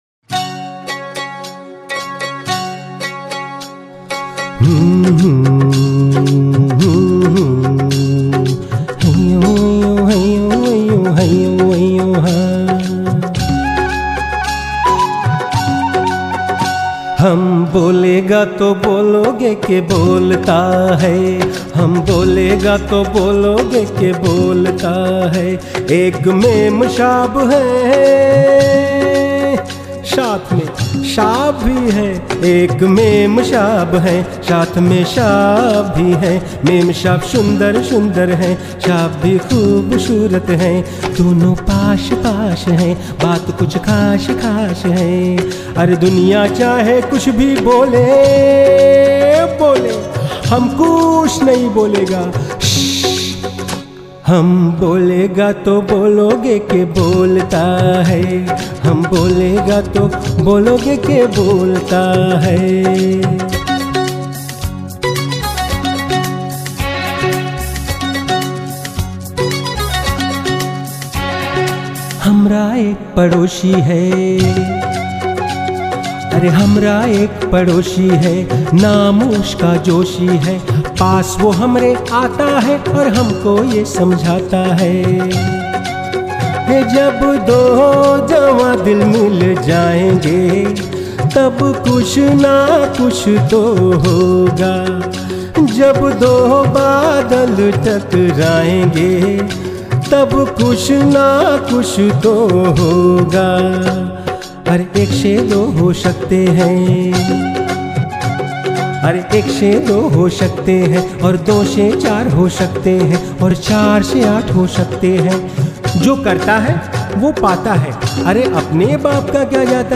His sweet and warm, rich and distinctively English and Hindi Voice has enhanced the image of many of today’s leading brands .
Sprechprobe: Sonstiges (Muttersprache):